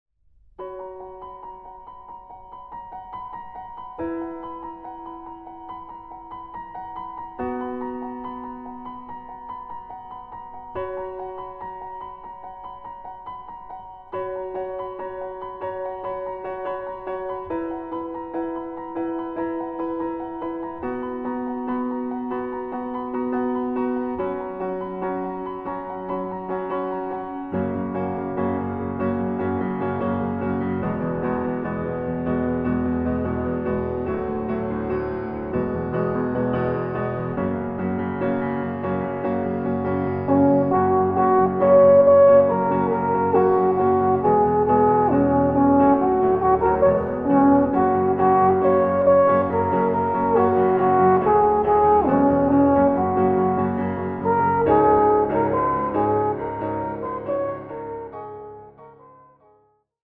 Traditional
Arr. for Alphorn and Piano